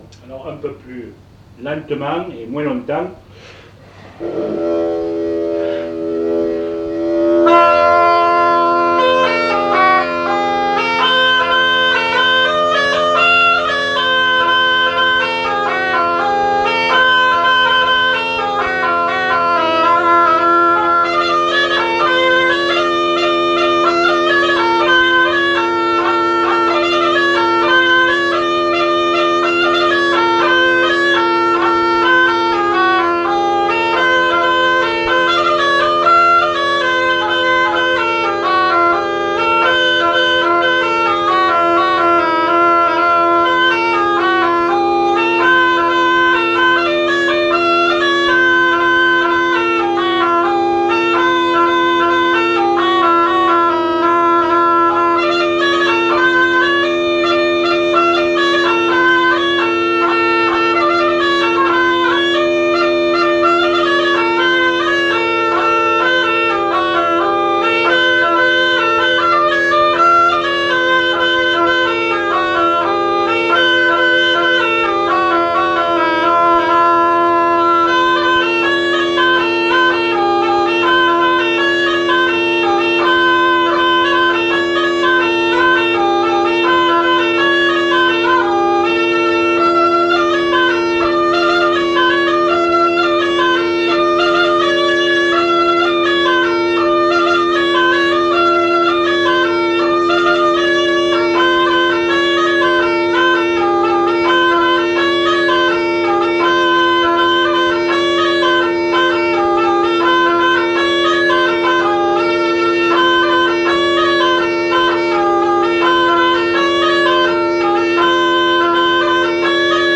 Aire culturelle : Cabardès
Genre : morceau instrumental
Instrument de musique : craba